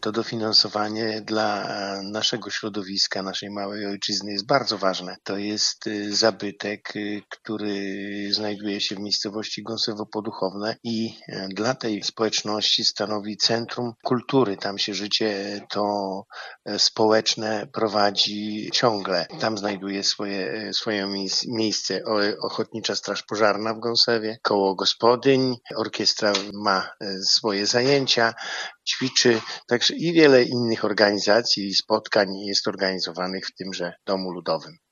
Wójt Gminy Sypniewo, Roman Gisztarowicz mówi, że Dom Kultury jest bardzo ważnym miejscem dla mieszkańców.